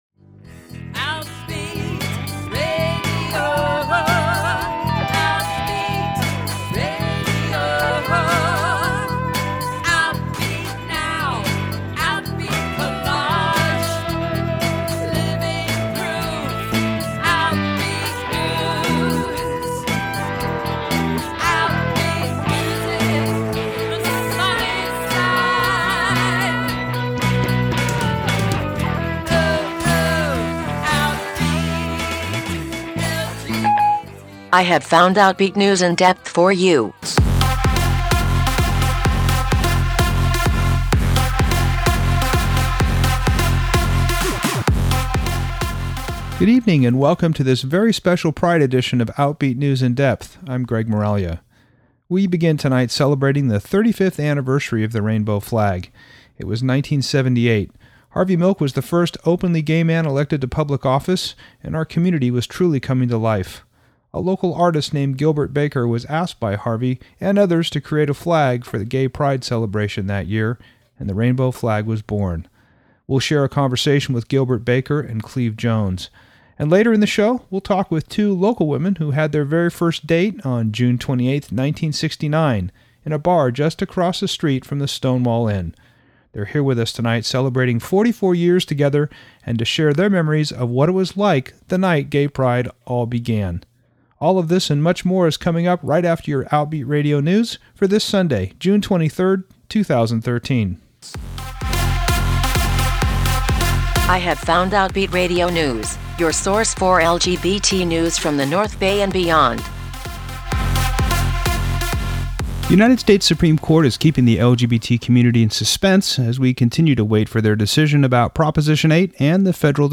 We begin by celebrating the 35th anniversary of the rainbow flag with an interview of the flag’s creator, Gilbert Baker, by long-time LGBT activist Cleve Jones. This interview took place at the GLBT Museum last year. They talked about why the flag was created and how it has become an international icon for gay pride and our fight for equality.